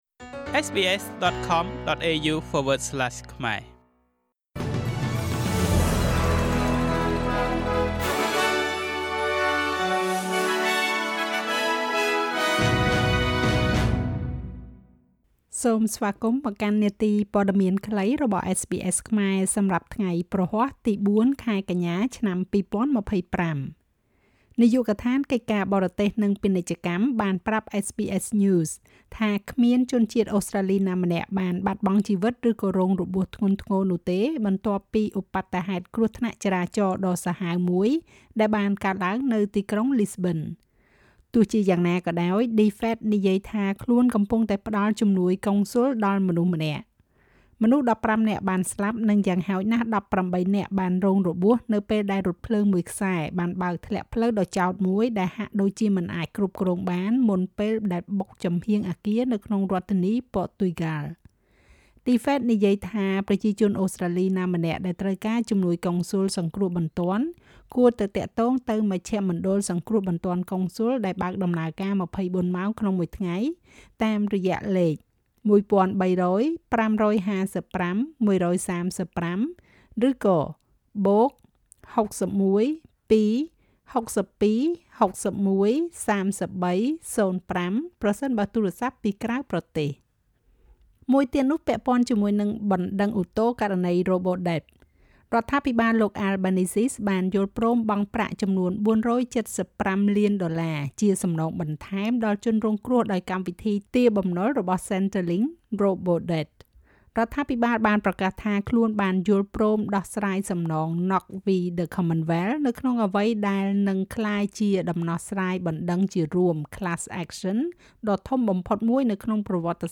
នាទីព័ត៌មានខ្លីរបស់SBSខ្មែរ សម្រាប់ថ្ងៃព្រហស្បតិ៍ ទី៤ ខែកញ្ញា ឆ្នាំ២០២៥